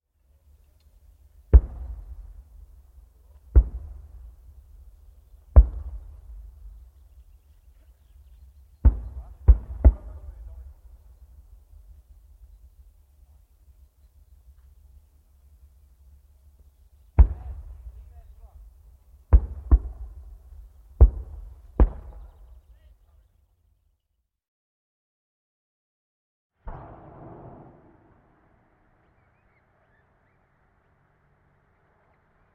Звук минометного обстрела вдали